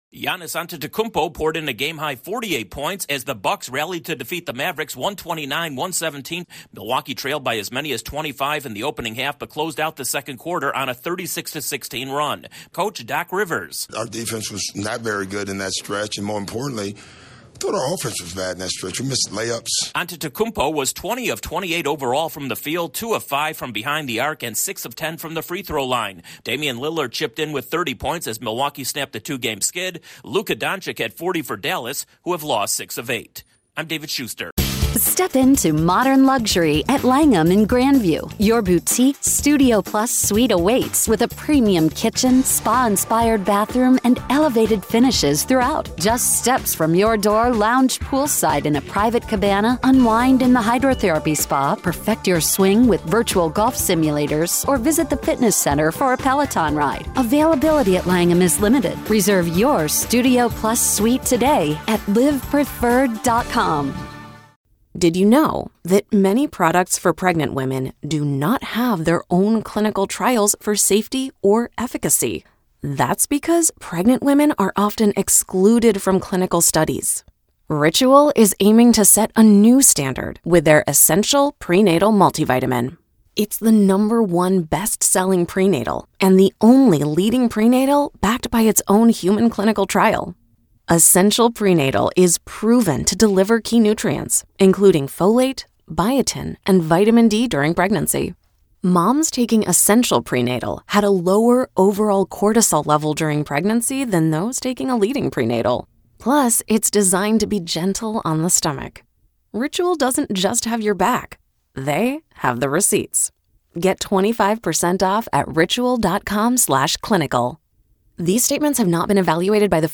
The Bucks get their first win for the new head coach. Correspondent